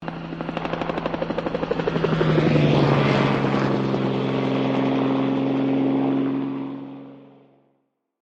Huey Close Fly By
SFX
yt_C7axlIEoxJ0_huey_close_fly_by.mp3